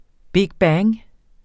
Udtale [ ˈbigˈbæːŋ ]